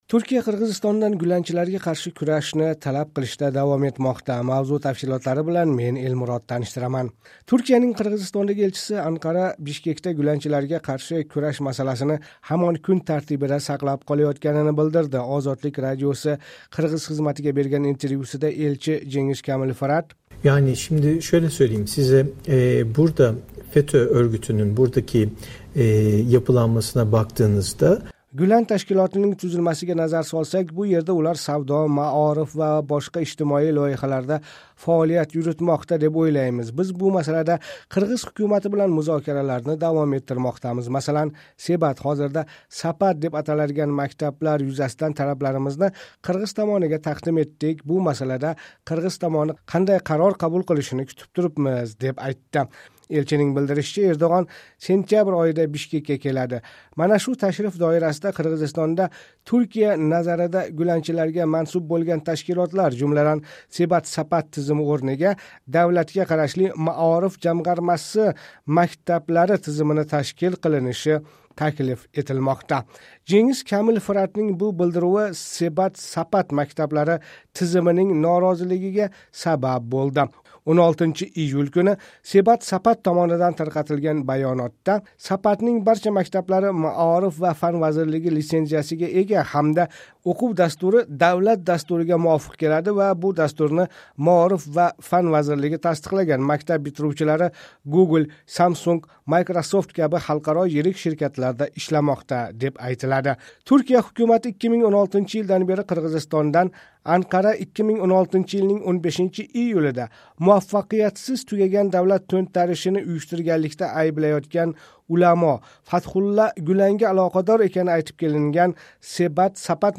Ozodlik radiosi qirg‘iz xizmatiga bergan intervyusida elchi Jengiz Kamil Firat: